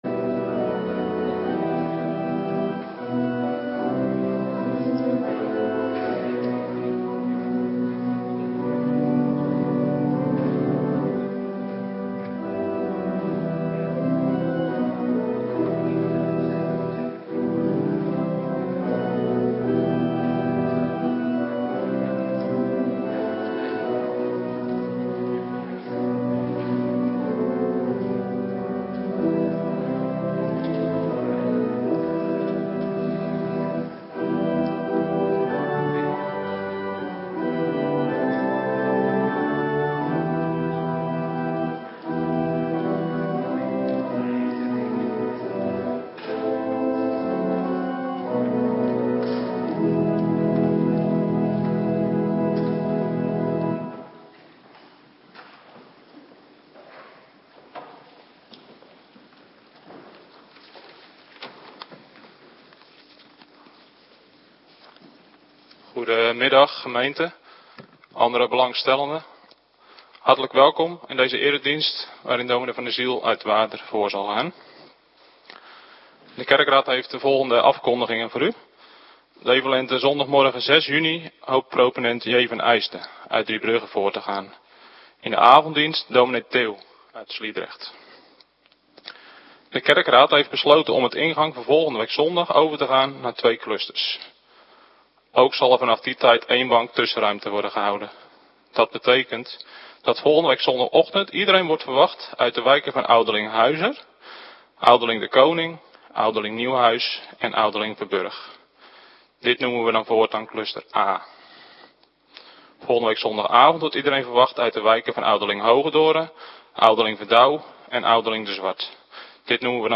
Middagdienst Heilig Avondmaal
Locatie: Hervormde Gemeente Waarder